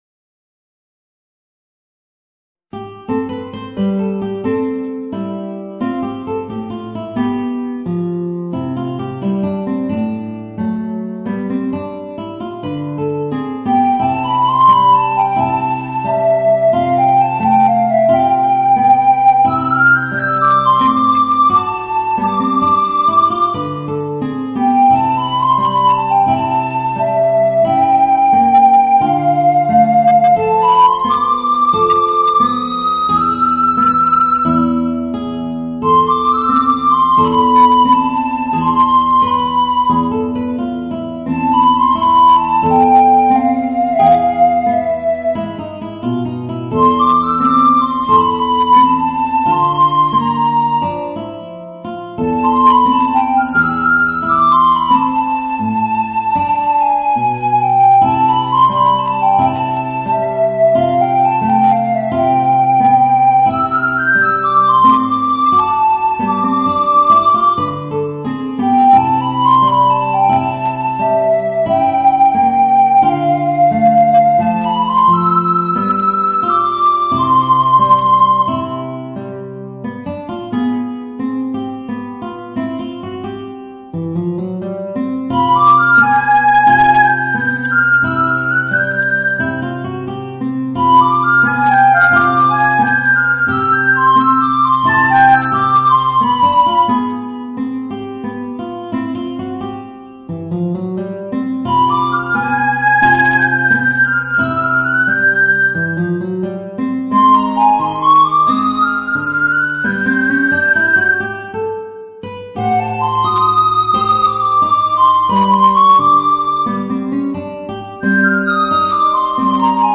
Voicing: Guitar and Soprano Recorder